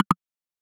switch_007.ogg